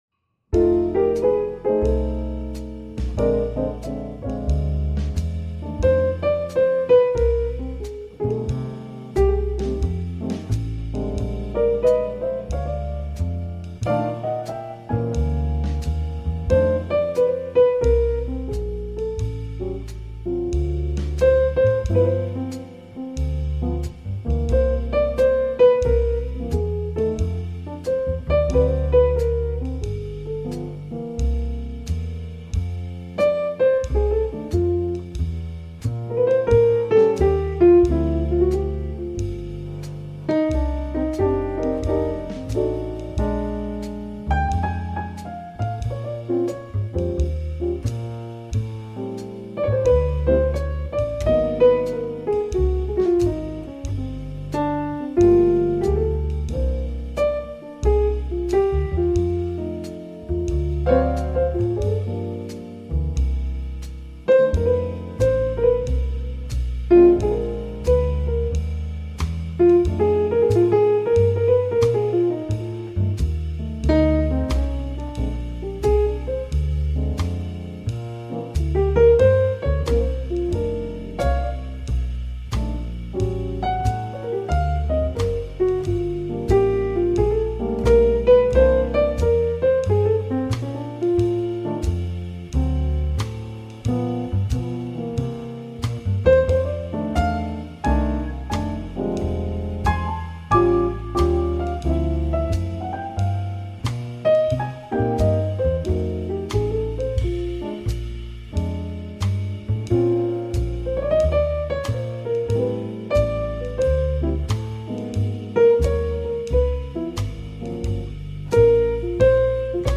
piano trio performance